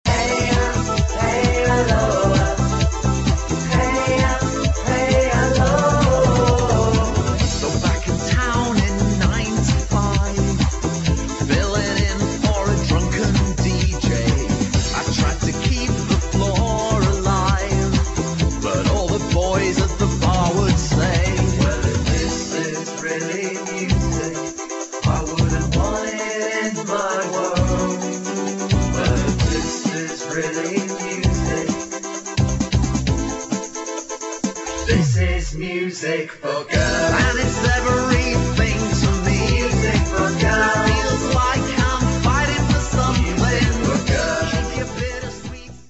[ HOUSE / ELECTRO ]